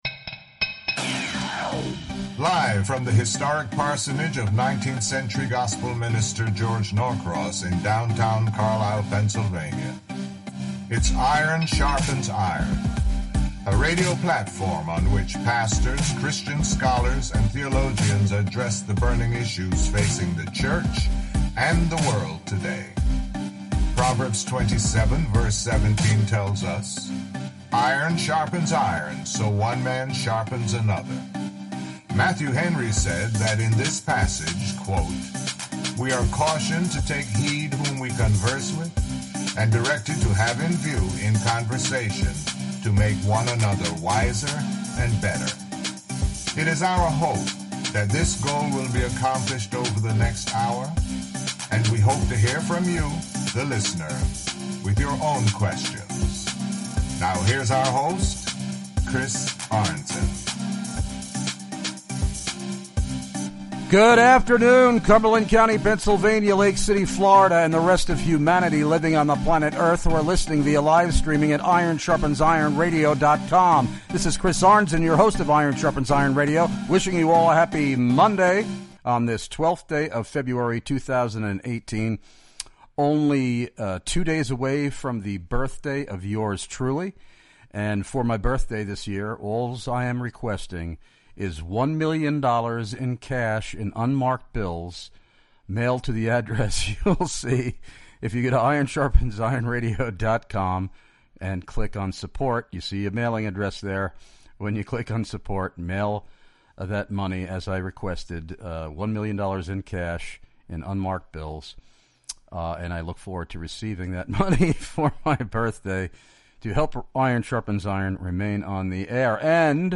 Interviews recorded LIVE at the 2018 G3 CONFERENCE!!!
These interviews were conducted on-site from the Iron Sharpens Iron Radio booth in the exhibition hall of the Georgia International Convention Center in Atlanta.